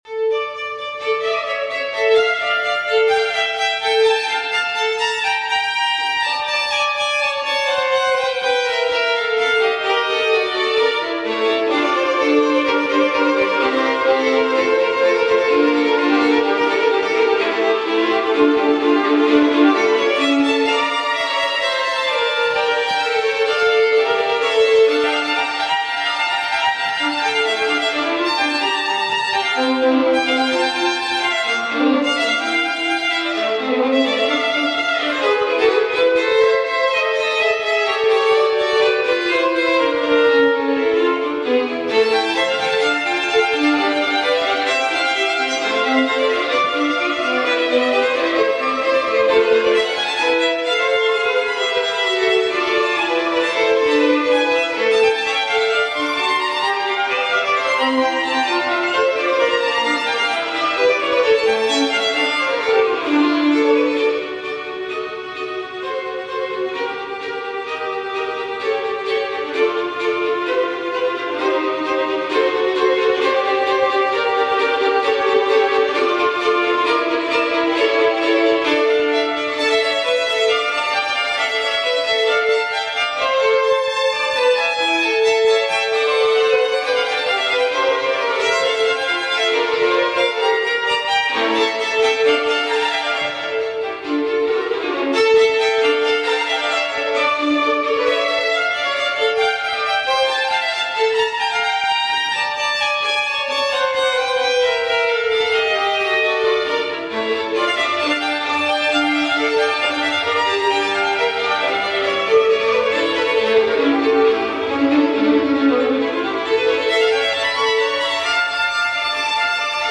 Klassische Musik auf Ihrer Veranstaltung
• Violin Duo Divites - A.Vivaldi Concerto grosso d-m (Violin Duo Divites)
violin-duo-divites-a.vivaldi-concerto-grosso-d-moll-allegro.mp3